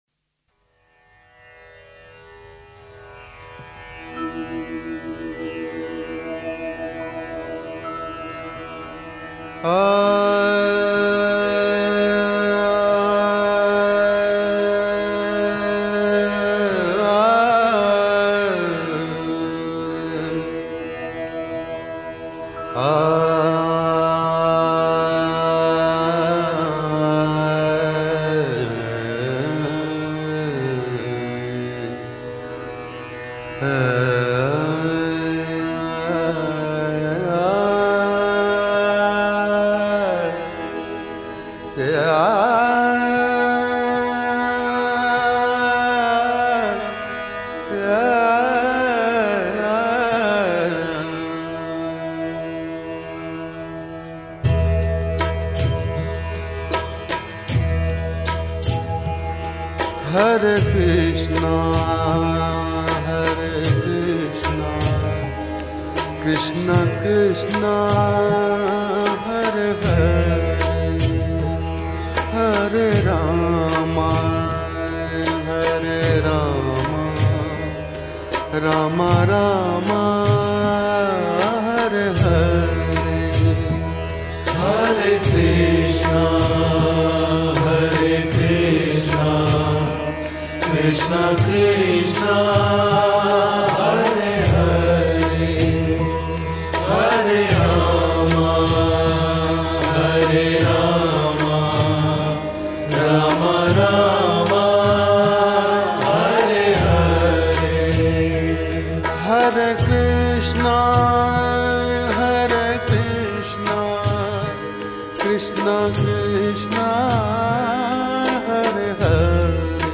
Devotional Songs